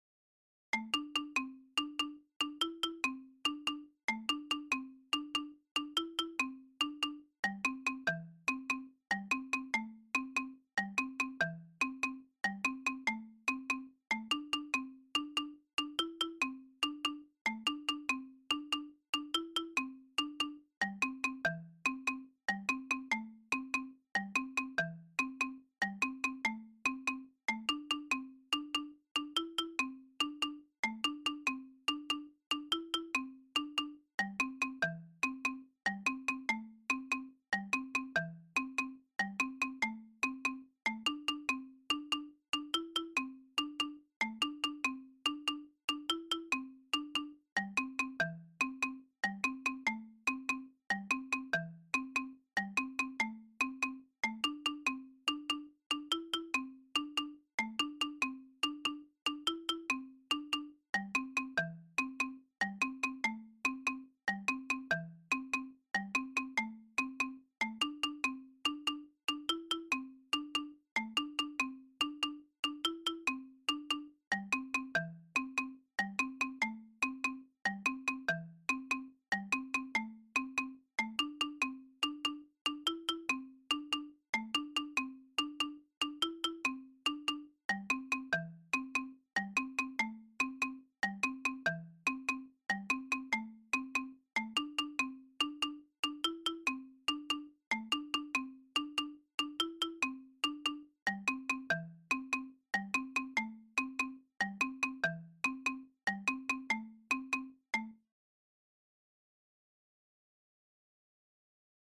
Bes - Bass part.mp3